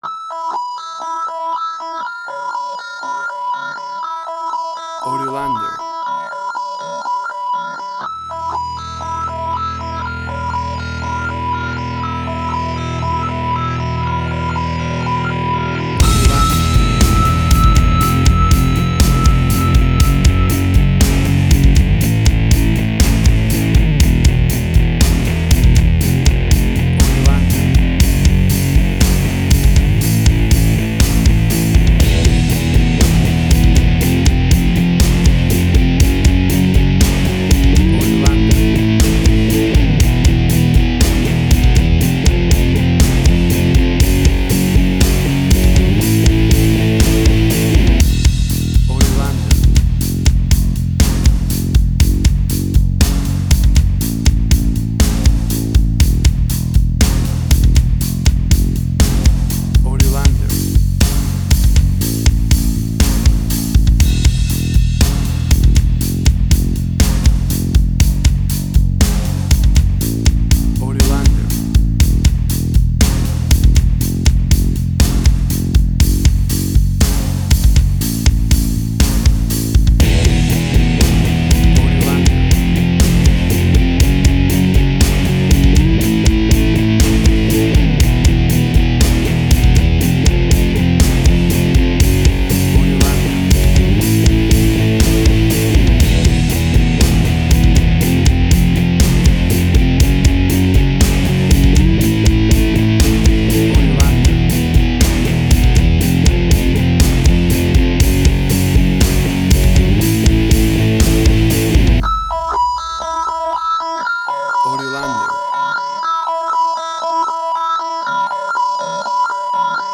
Tempo (BPM): 60